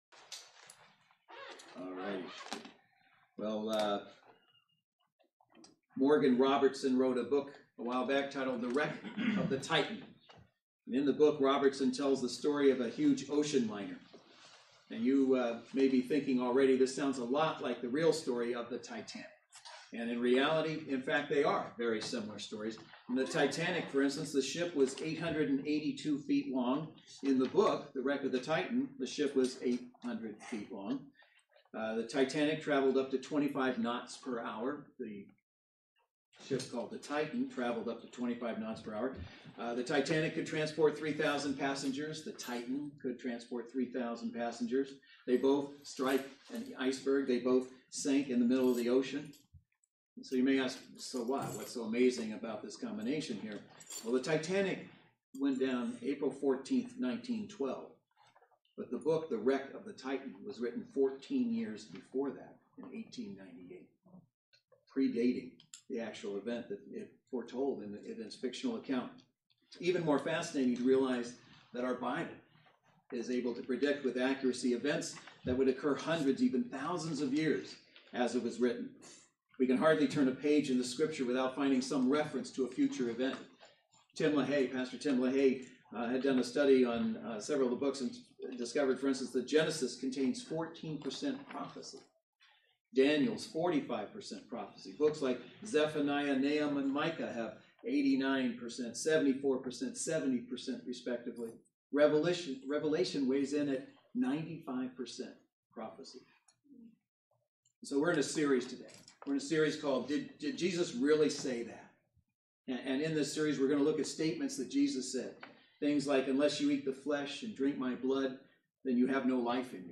Mark 13:32 Service Type: Saturday Worship Service Bible Text